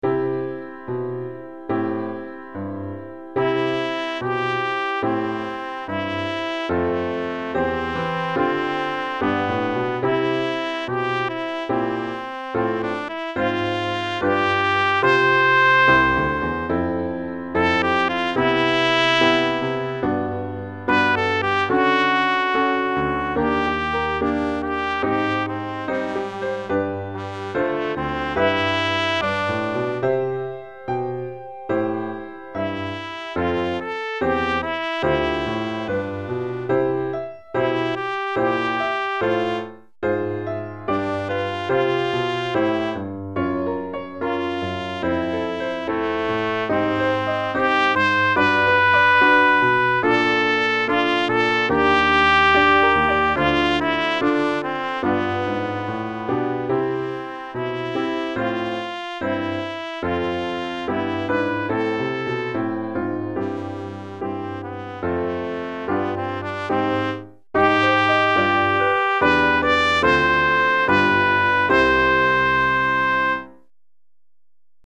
Trompette en Sib et Piano